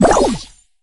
death_plop_01.ogg